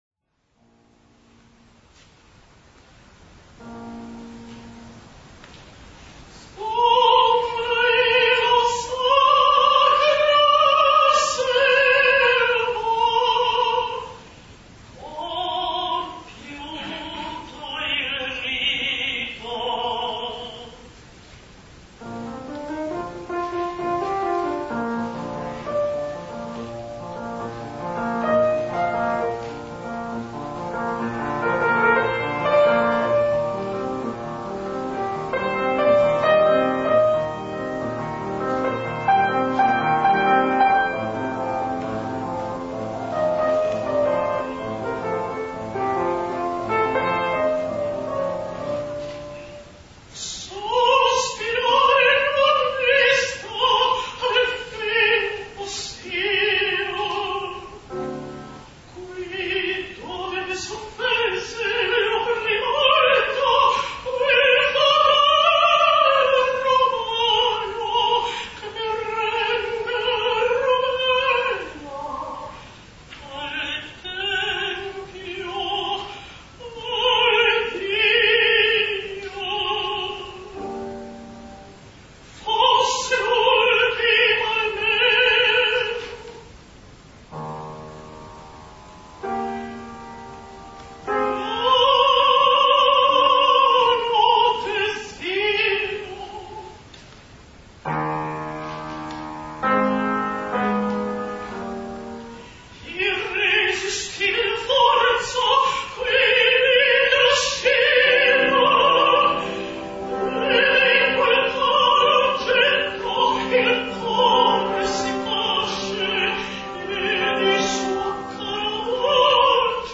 Audición - Taller (2´18 - 541 KB)